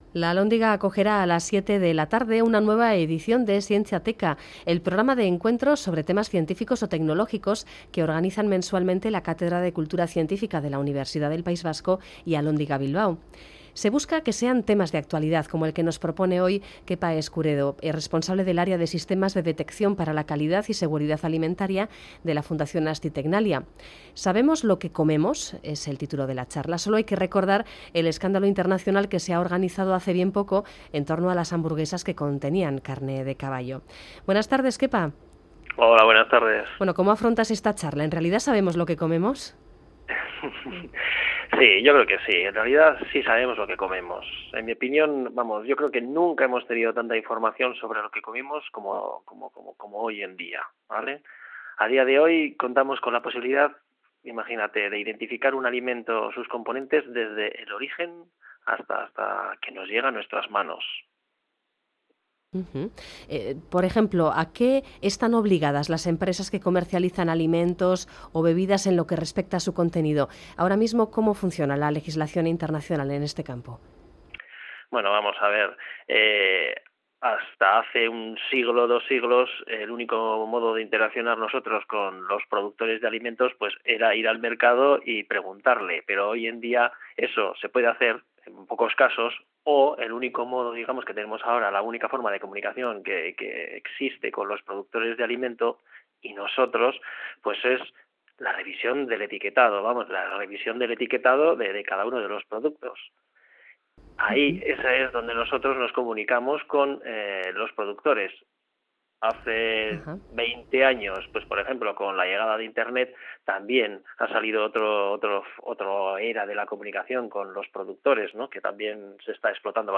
¿Sabemos qué comemos?|entrevista